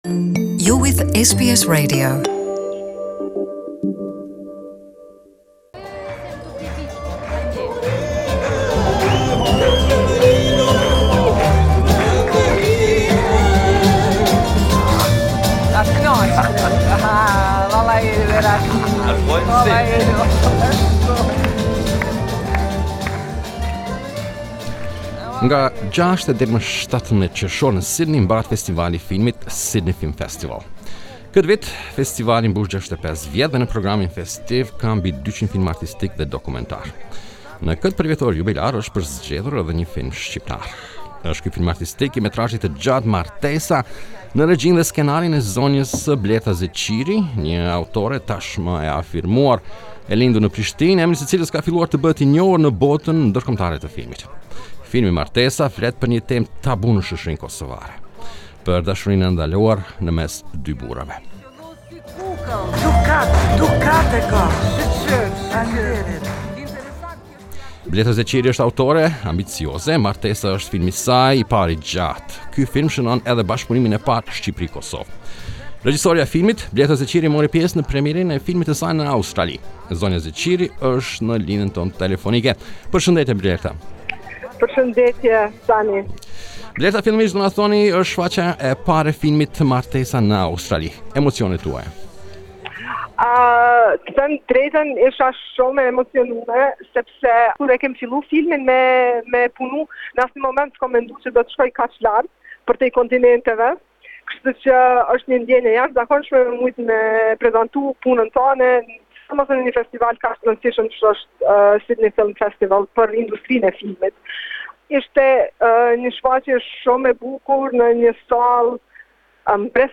Ne zhvilluam nje interviste me te.